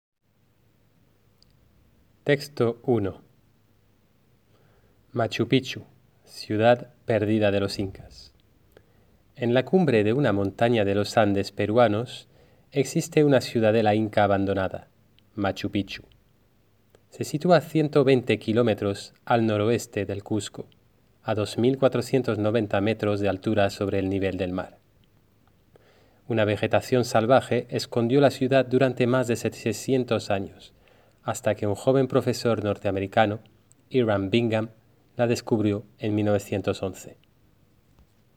Lectura del profesor